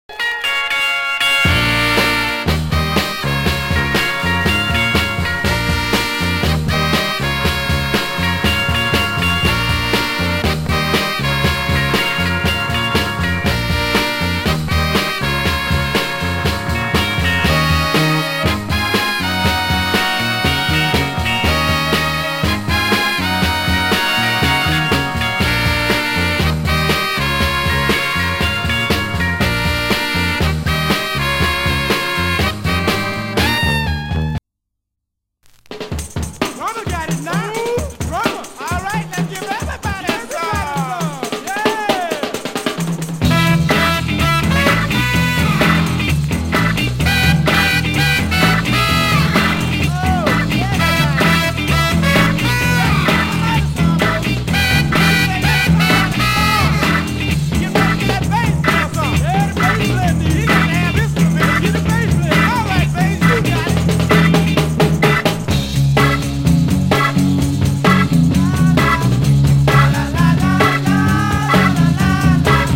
ストンプするビートに分厚いホーンが決まったA面に掛け声とオルガンで押しまくるB面。
(税込￥2750)   SOUL, R&B